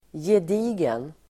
Uttal: [jed'i:gen]